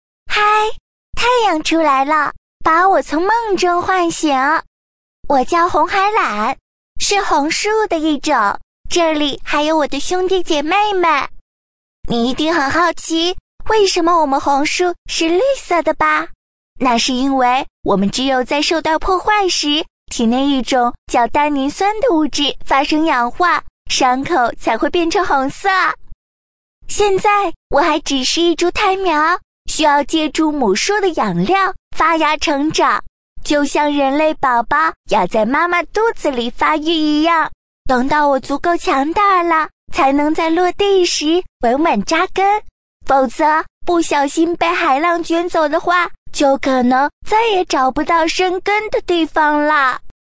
【女36号模仿】（女童音）红海榄
【女36号模仿】（女童音）红海榄.mp3